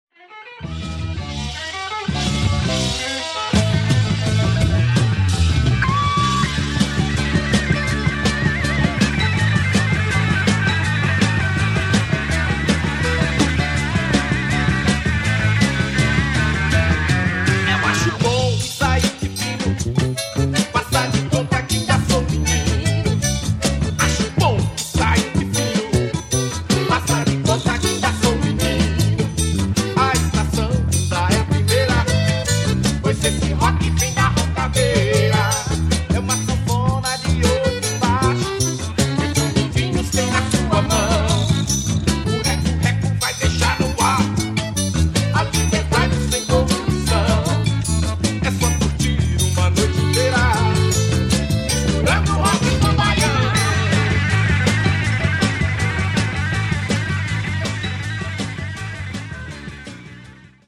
Psychedelic Brazilian re-issue heat